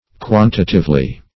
Search Result for " quantitively" : The Collaborative International Dictionary of English v.0.48: Quantitively \Quan"ti*tive*ly\, adv.